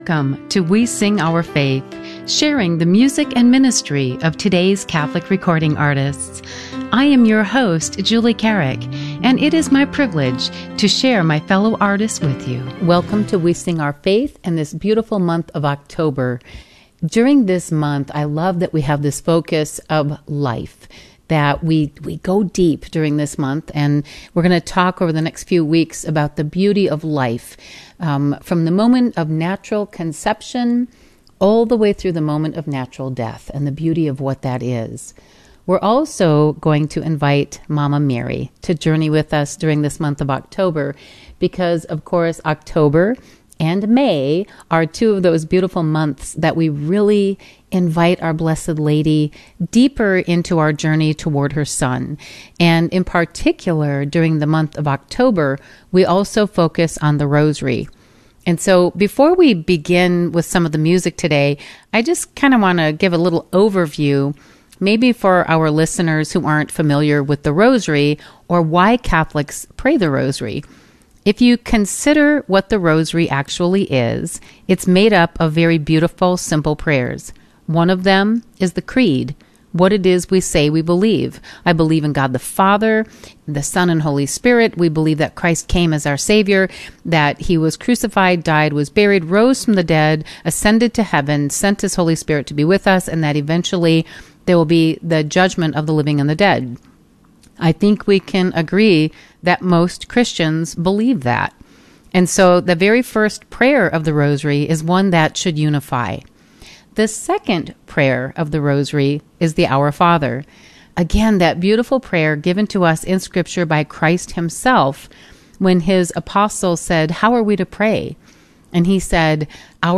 A new uplifting Catholic music show